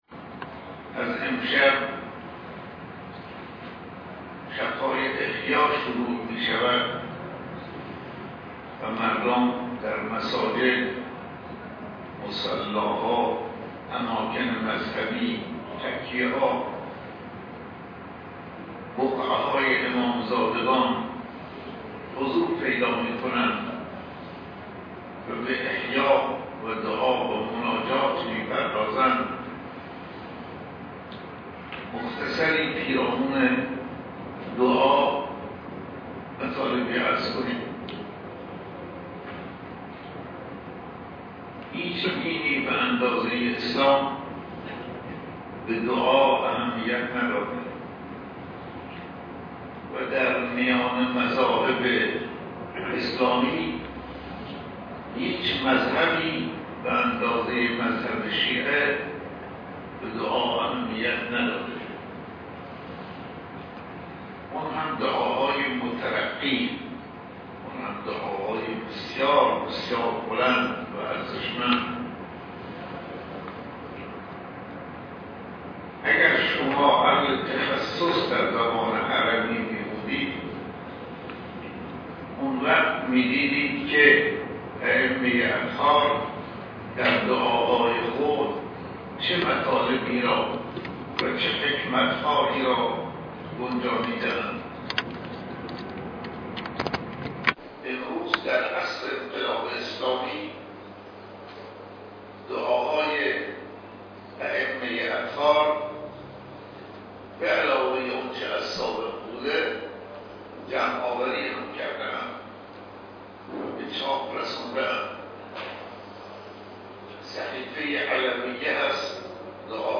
سخنرانی روز ۱۸ ماه رمضان - صوتی :: پایگاه خبری مسجد حضرت آیت الله شفیعی اهواز
‌ سخنرانی حضرت آیت الله شفیعی روز ۱۸ ماه رمضان (یکشنبه چهاردهم تیرماه)